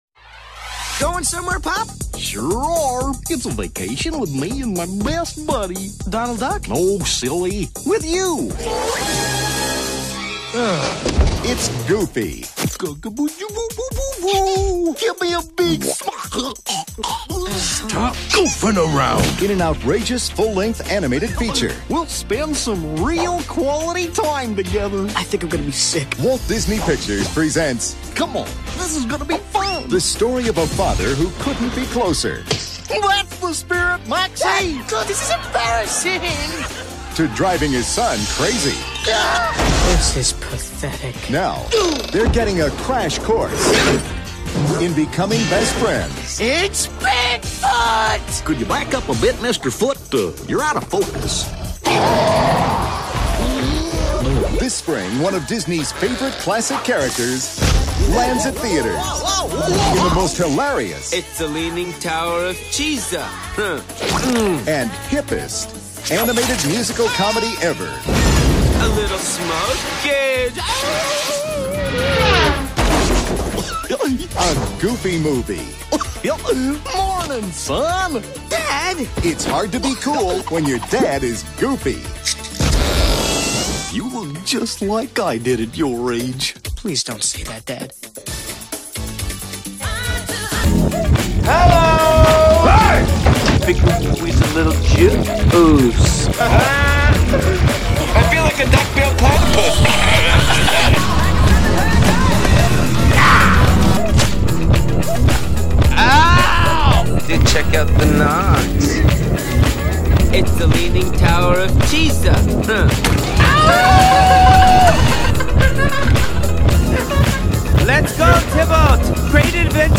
Luckily for our hero, A Goofy Movie (1995) happens to be a generational touchstone, and An Extremely Goofy Movie (2000) is… surprisingly watchable? Anyway, we go to some dark places in this episode, so to avoid incongruity with the kid-friendly movies’ themes, we bleeped out all the cuss words with fun Goofy sounds in the tradition of our Cat in the Hat episode.